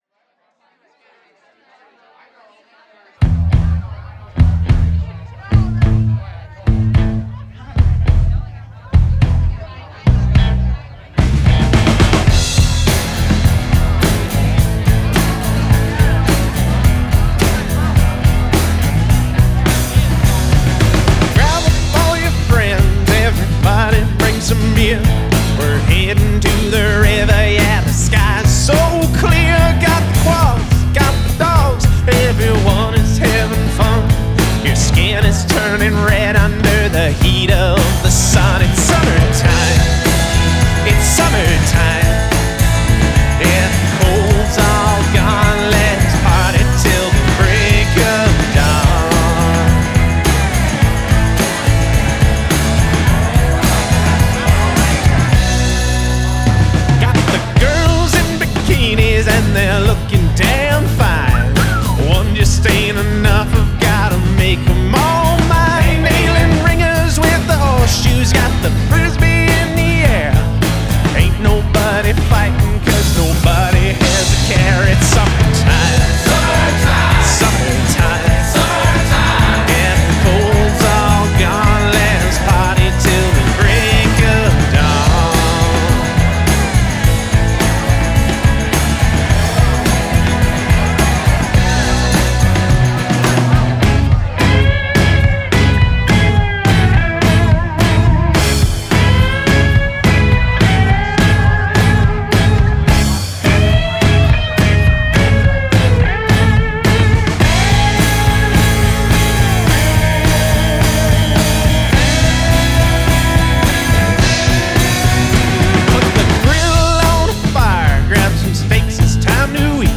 (that will include 7 original brand new studio recordings)